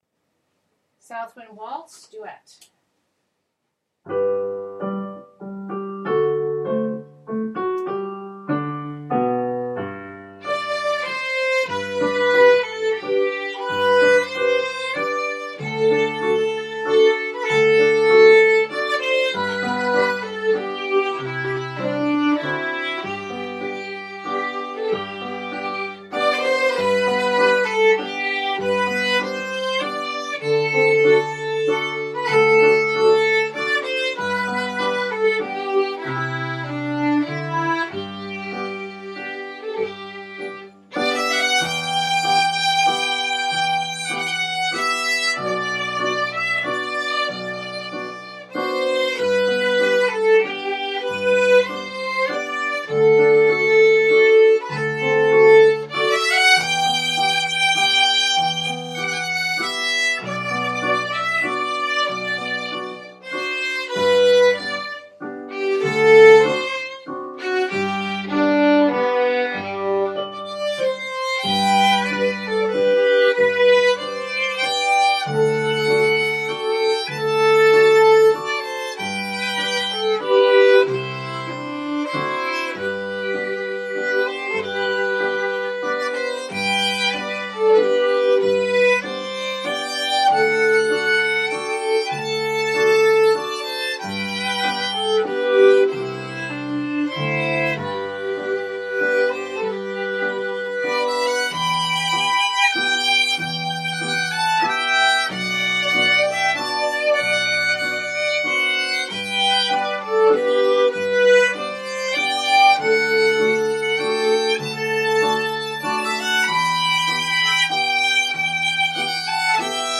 Southwind Waltz duet.mp3